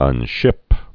(ŭn-shĭp)